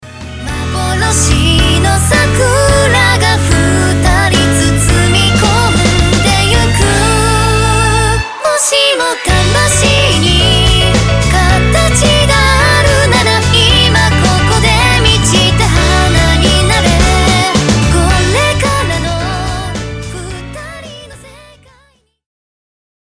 電波ソング